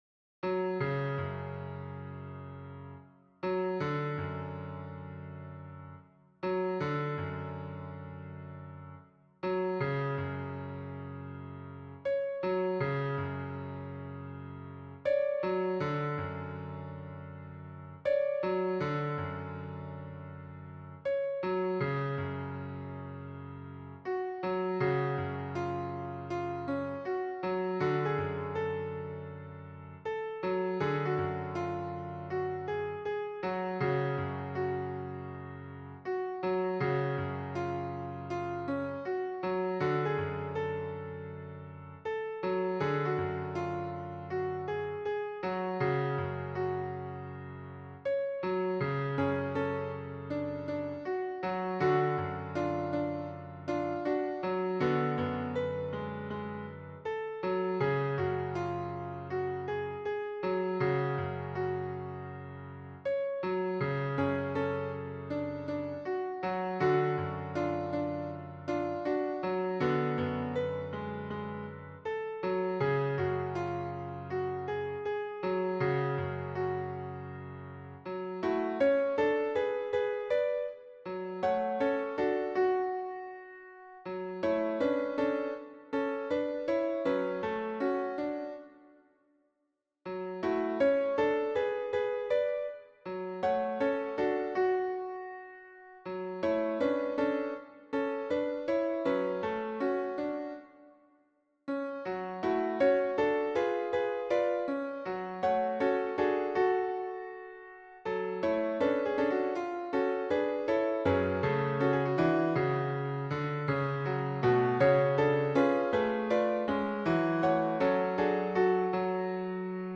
- berceuse traditionnelle norvégienne
MP3 version piano
Tutti